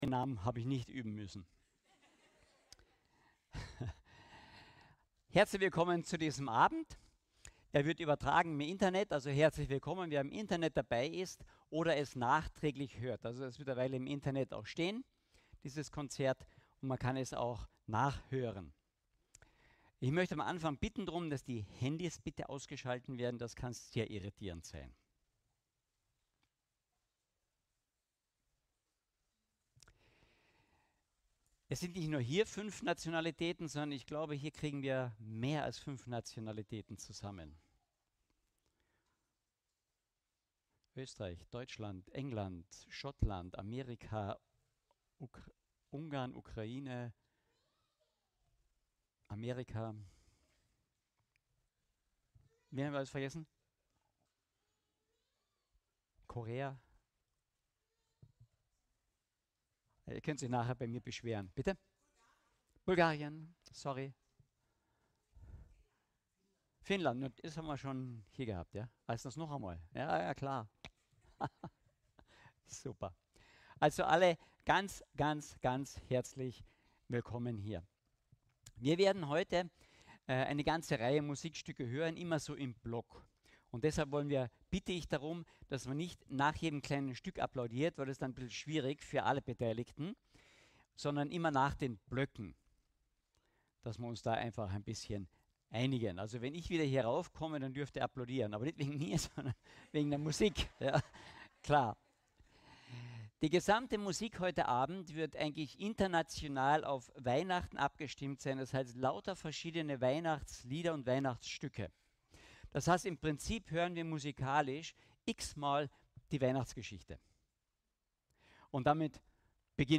Adventgottesdienst-mit-klassischer-Musik-2024.mp3